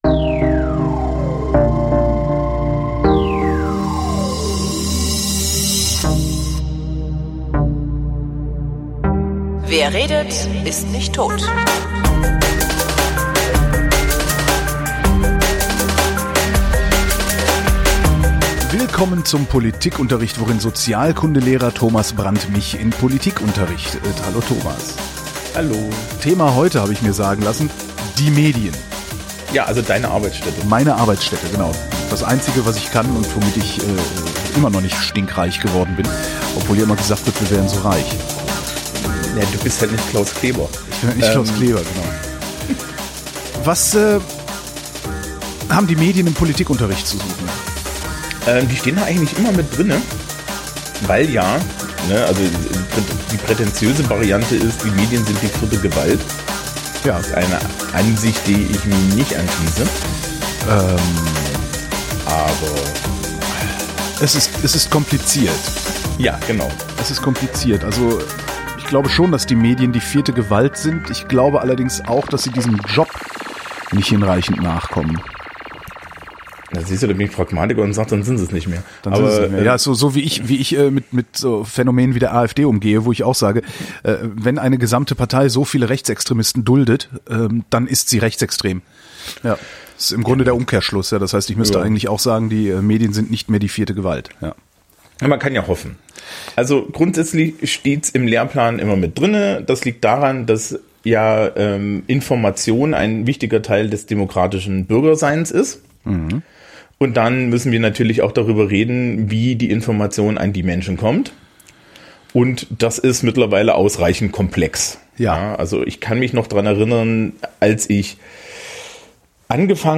Weil in jeder Sendung geredet wird, habe ich mir erlaubt, das Projekt nach einem Satz aus Gottfried Benns Gedicht “Kommt” zu benennen.